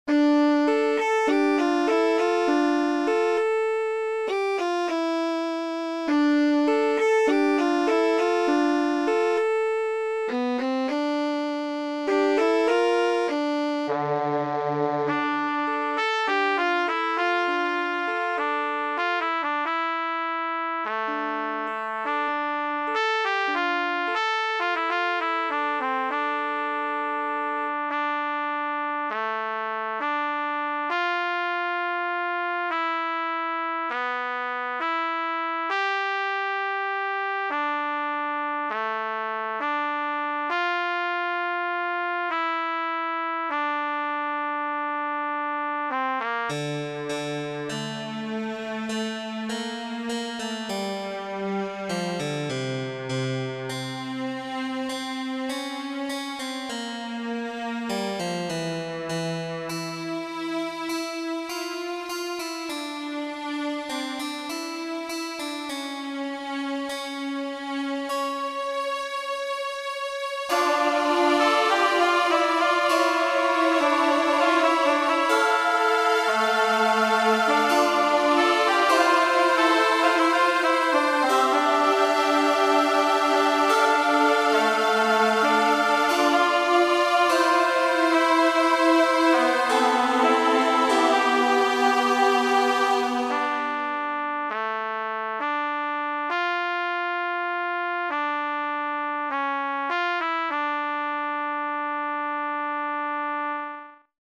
The music I created lacked everything, including a time signature, and you could hear it.
Concerto, 2005